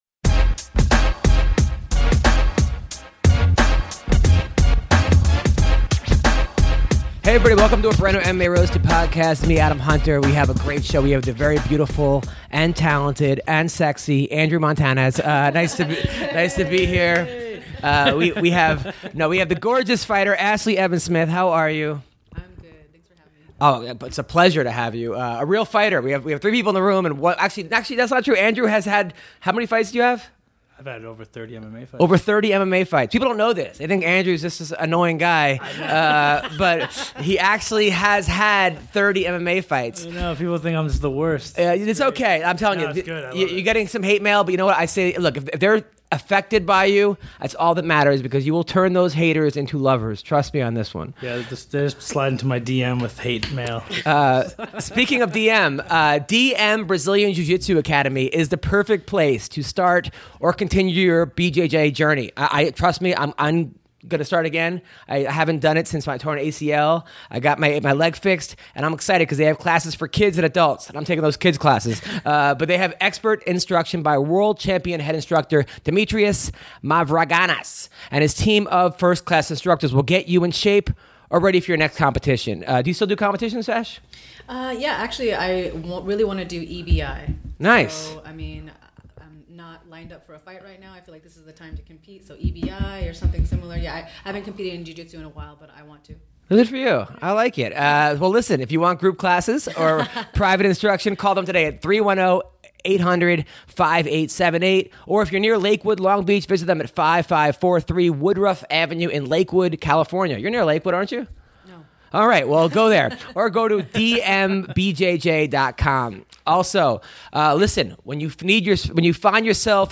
in Studio
Ben Askren calls up and talks about why he is retiring after his next fight, his predictions on GSP vs Bisping, his thoughts on standing for the National Anthem and more.